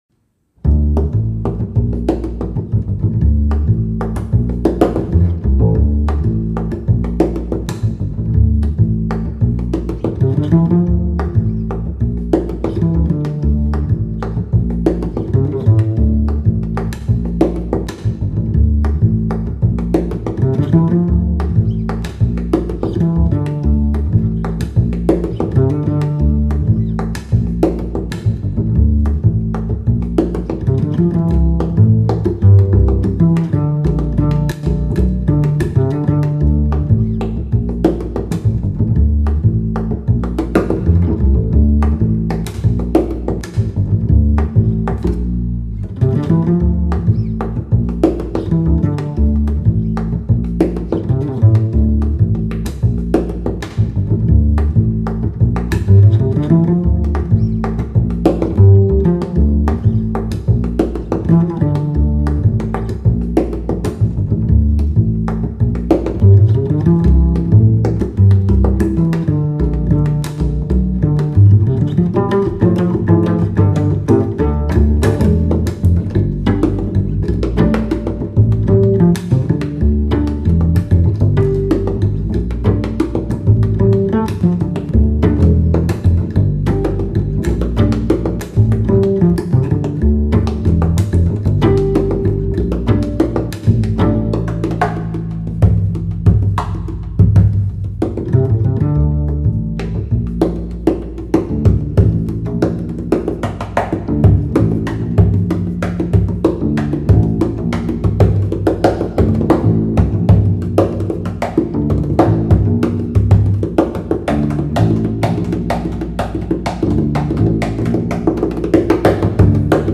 Приятная мелодия только на контрабасе